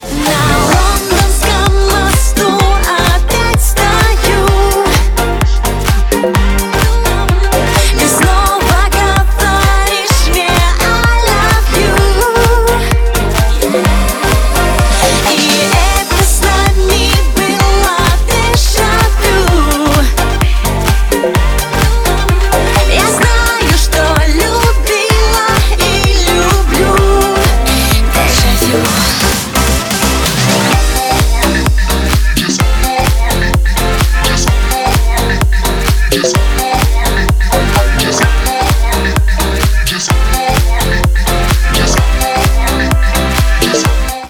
• Качество: 128, Stereo
поп
dance
русская попса